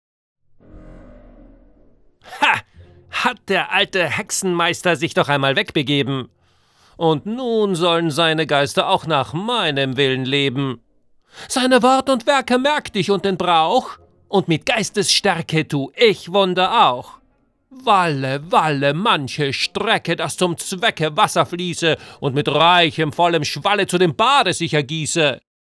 Deutscher Sprecher, Off Sprecher,eigenes Studio vielseitige,warme, durchsetzungsfähige Stimme für Werbung, Dokumentation, Fernsehbeiträge, Trailer,e-learning, Imagefilm, Lyrik und Hörbuch
Sprechprobe: Sonstiges (Muttersprache):
versatile german voice over artist